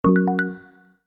new-message-1.ogg